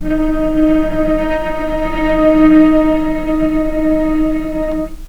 vc-D#4-pp.AIF